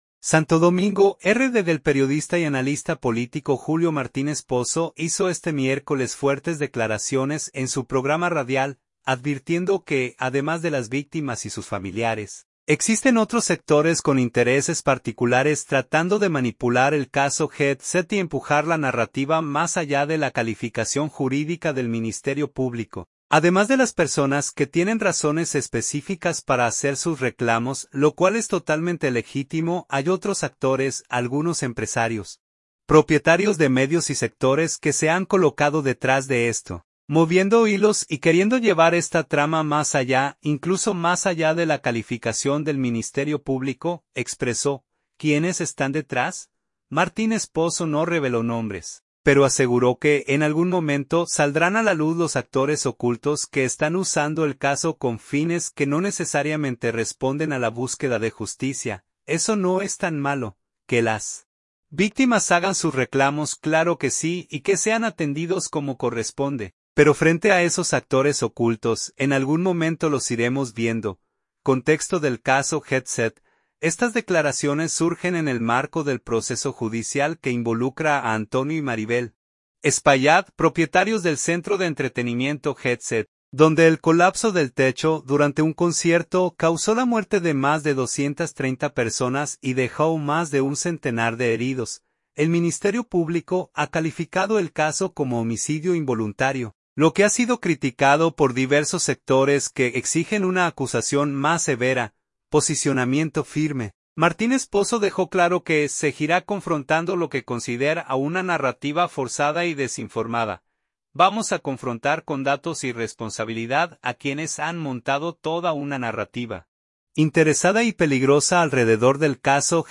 fuertes declaraciones en su programa radial